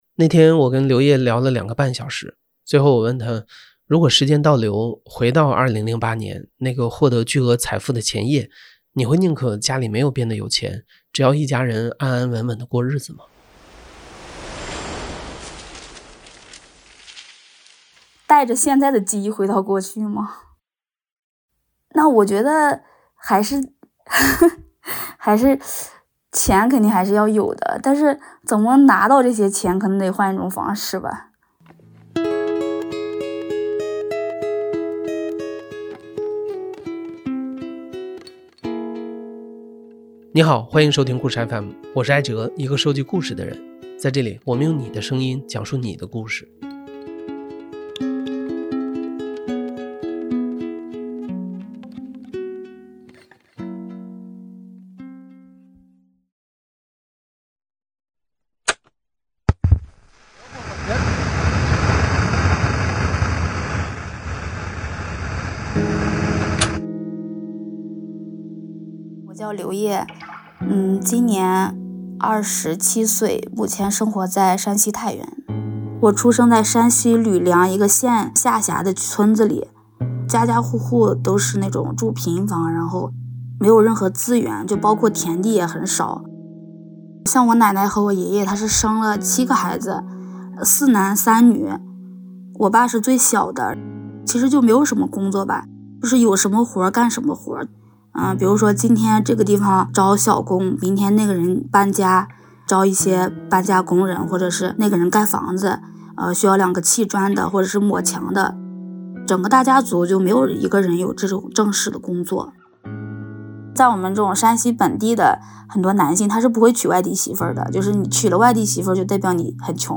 故事FM 是一档亲历者自述的声音节目。